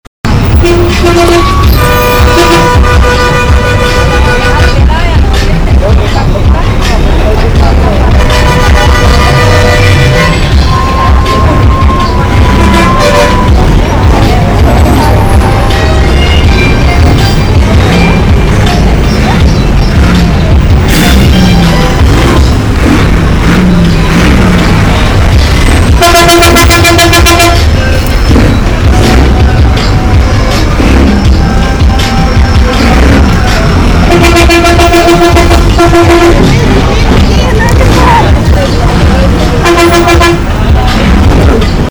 By the way, this is how it sounds on busy bus terminals: